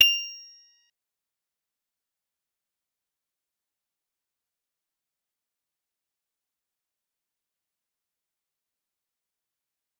G_Musicbox-F8-mf.wav